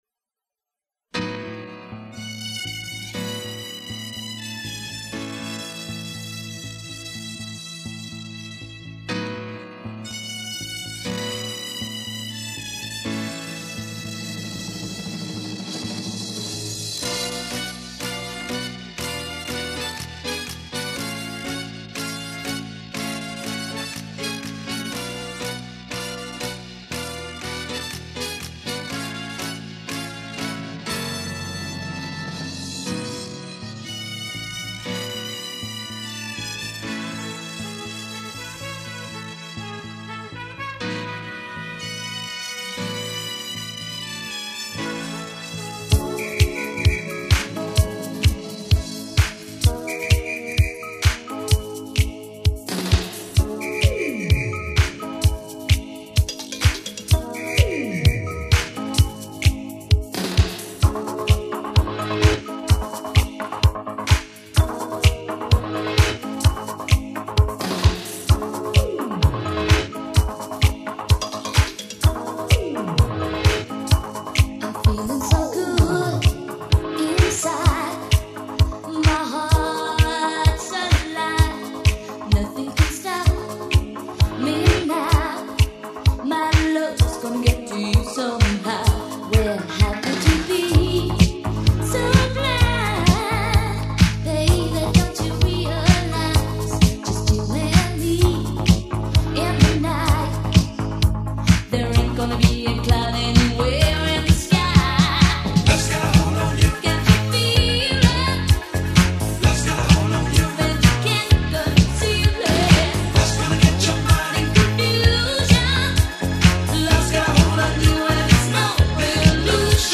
A mixture of dance, hi NRG, house and pump music.